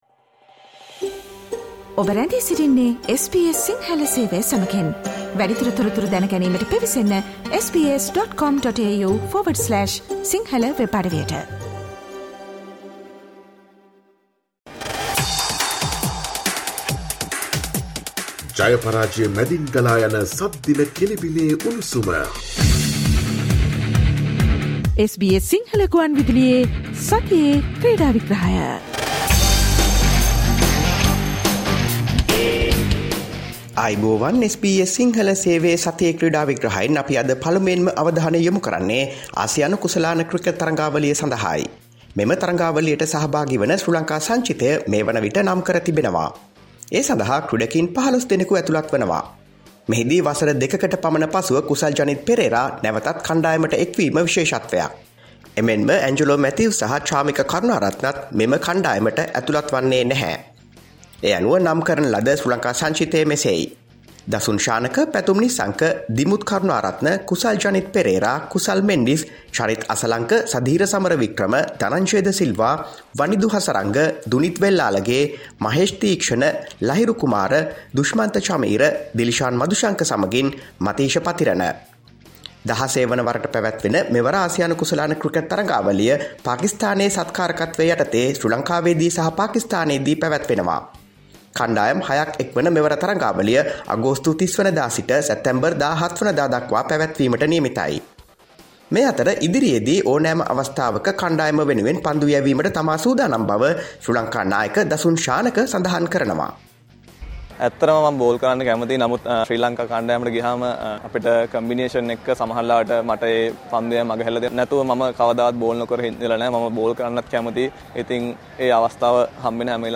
Listen to the SBS Sinhala Radio weekly sports highlights every Friday from 11 am onwards